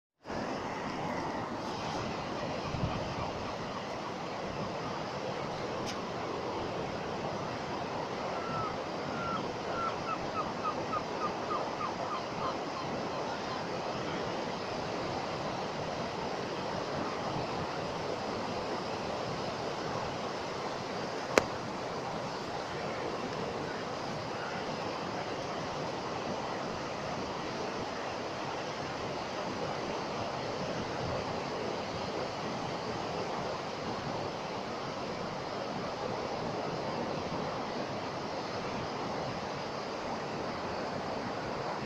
A nós, interésanos, sobre todo, os da natureza: as ondas, o vento, os seres vivos que habitan as nosas terras (non esquezas que estamos nun dos paraísos das aves da nosa costa).
praia da Frouxeira e outro na Lagoa.
audio_PRAIA.m4a